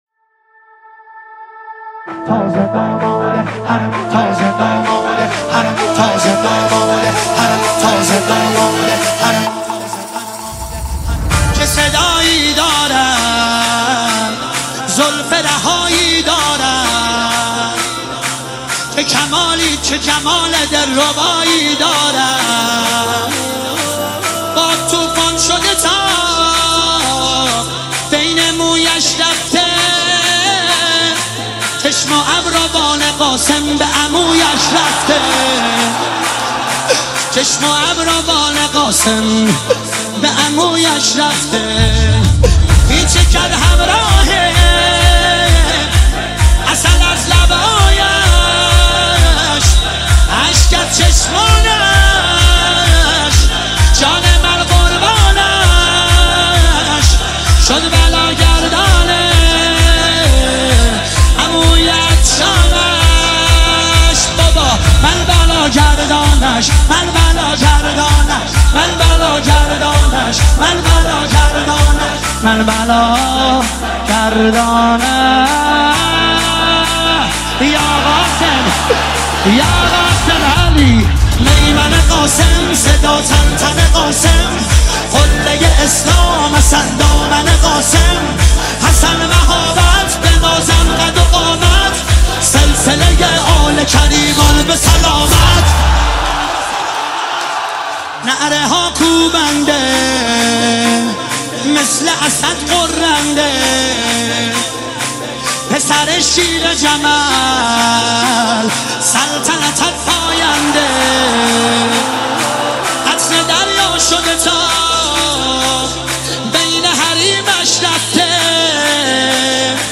مداحی های منتخب
شب ششم محرم